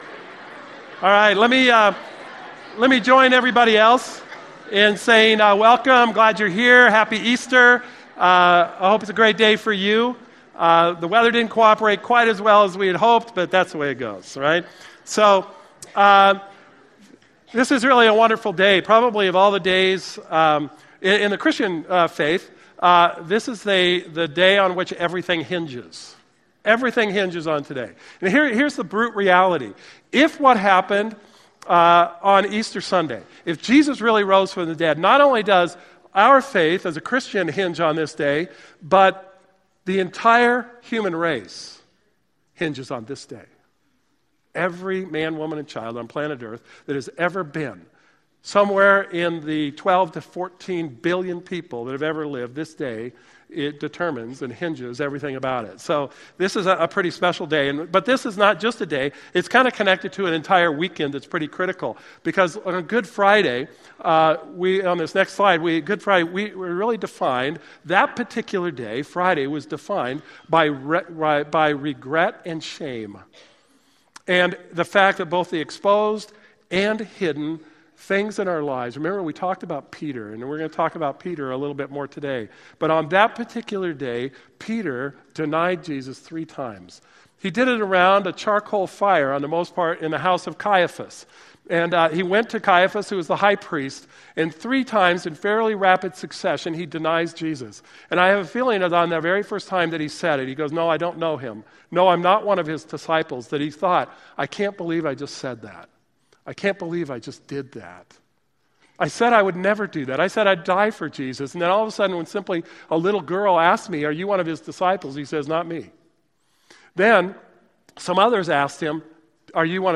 Easter-2017-Message-Audio.mp3